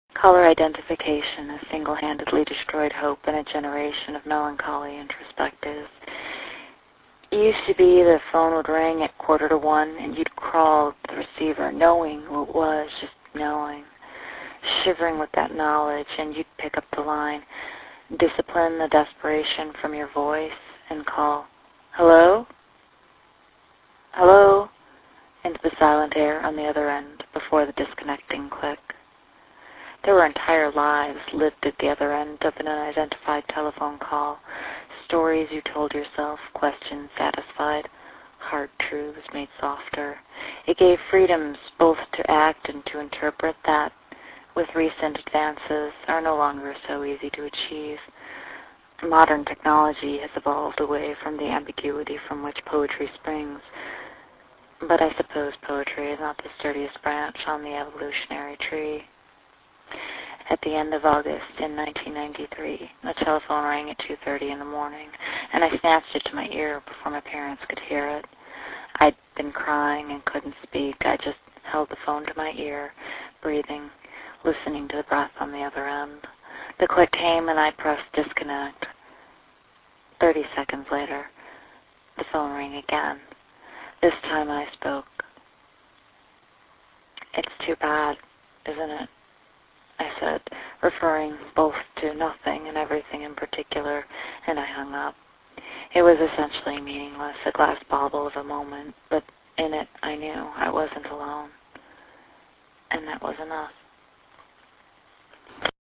Sorry I missed you" (1.1M) Jun 12, 2007 Isn't it impressive, how it sounds like I'm talking into a cordless VTech telephone at 4:07 in the morning?
It fits the motif, though chances are that motif exists because, after all this time, I can't find the microphone I use for voice recordings.